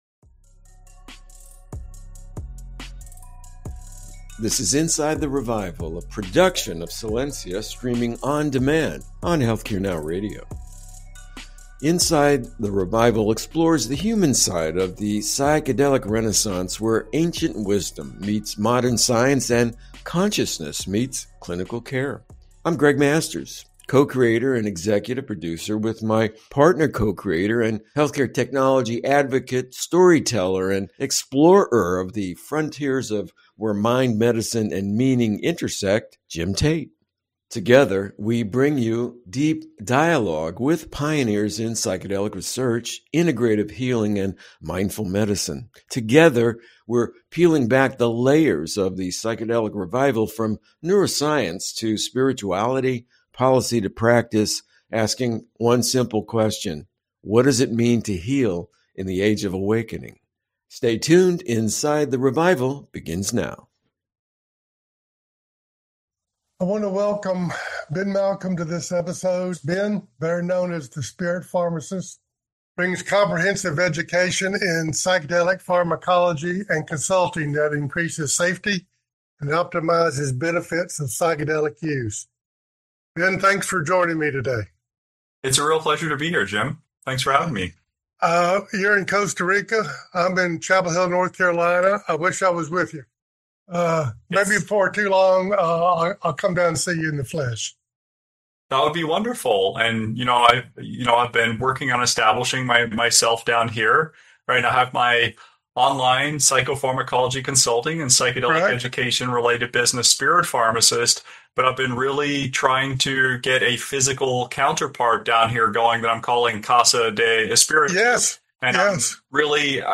rain-and-natural-soft-melody-track